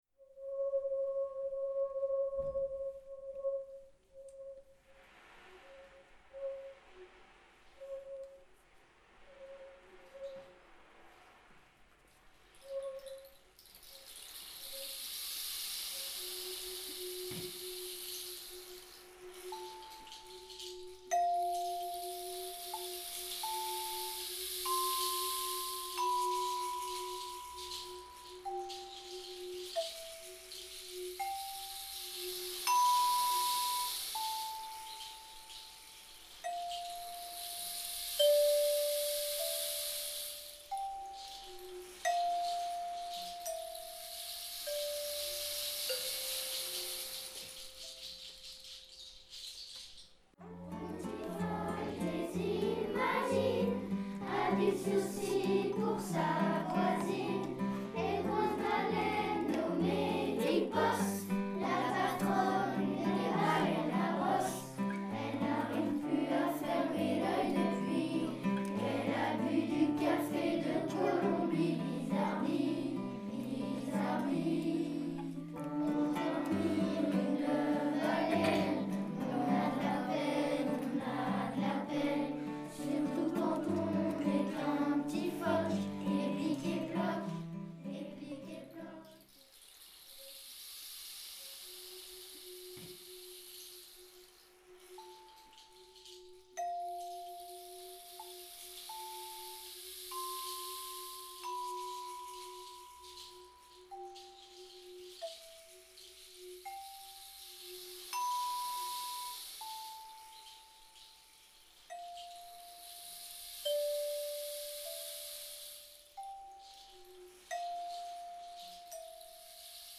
Berceuse-pour-une-baleine-1-1.mp3